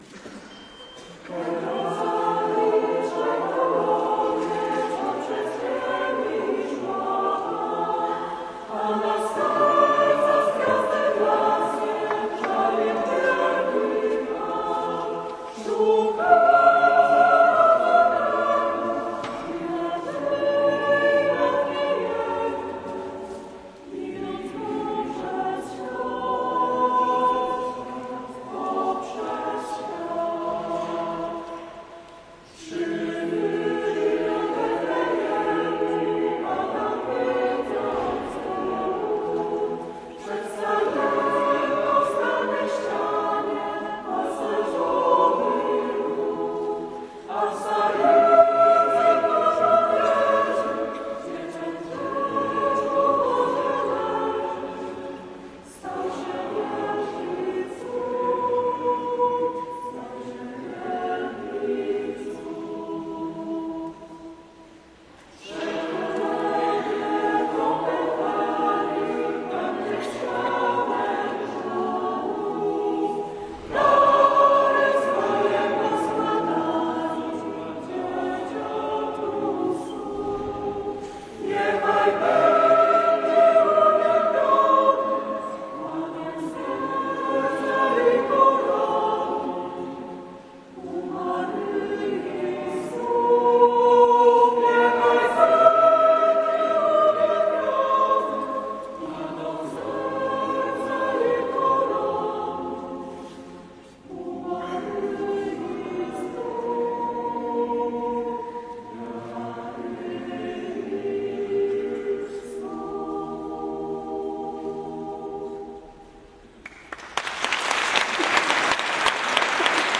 Chór parafii Dobrego Pasterza – Lubelski Festiwal Chórów Parafialnych
Czterogłosowy chór parafialny tworzą pasjonaci wspólnego śpiewania. Zespół łączy pokolenia, a w poszczególnych sekcjach jest miejsce i dla uczącej się młodzieży, i zapracowanych dorosłych, i aktywnych seniorów.